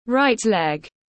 Chân phải tiếng anh gọi là right leg, phiên âm tiếng anh đọc là /raɪt leg/.
Right leg /raɪt leg/